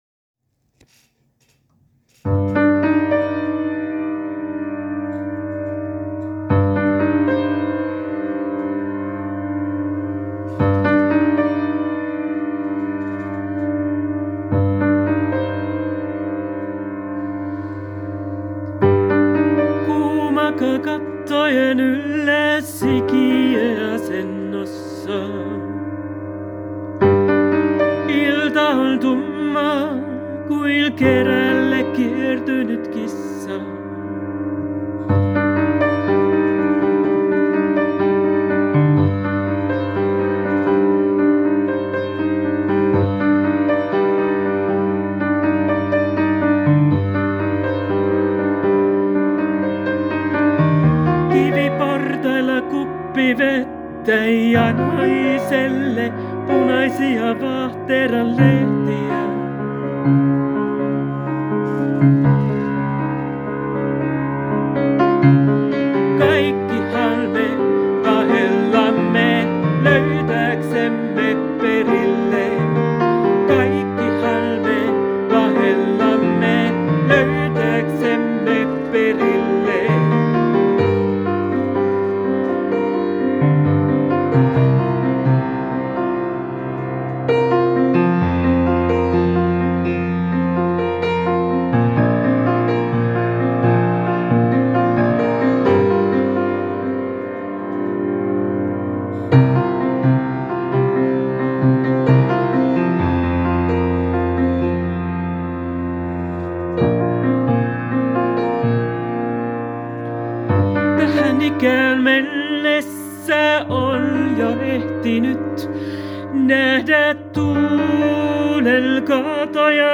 pianisti/laulaja
Trubaduuri